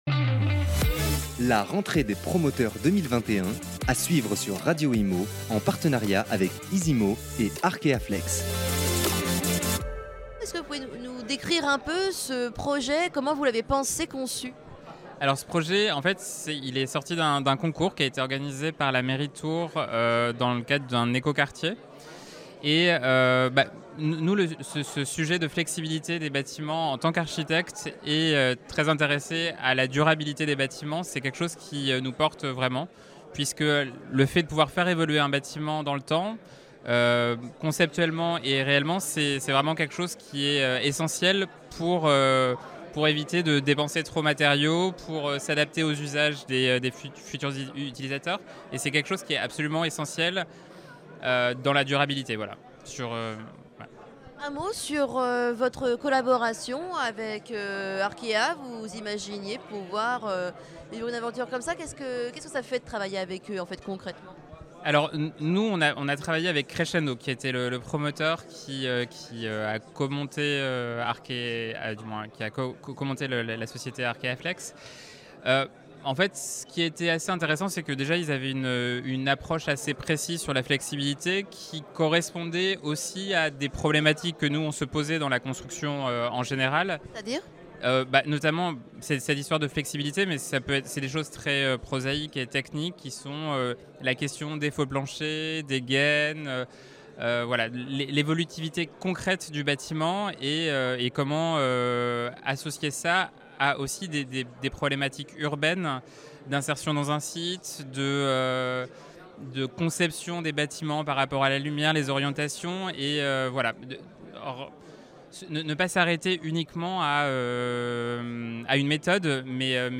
SEANCE DE QUESTIONS / REPONSES AVEC LES PARTICIPANTS EN SALLE
Radio Immo était en direct le mercredi 6 janvier 2016 matin, dans le cadre d'une table ronde sur la COP 21, et l'impact sur le métier de gestionnaire immobilier